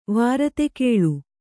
♪ vārate kēḷu